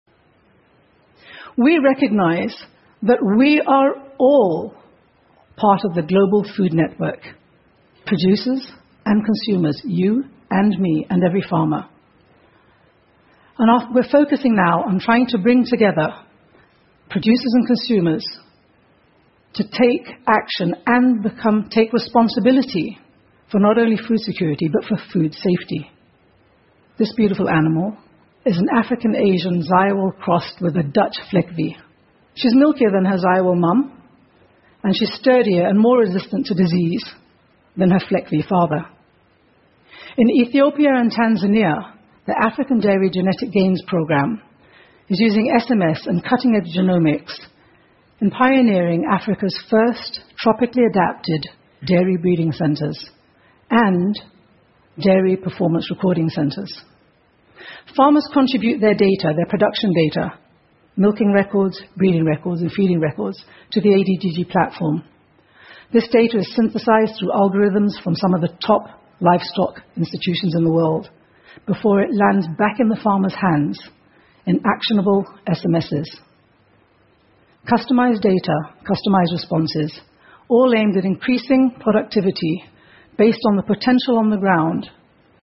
TED演讲:我们是如何通过手机短信帮助饥饿的孩子填饱肚子的() 听力文件下载—在线英语听力室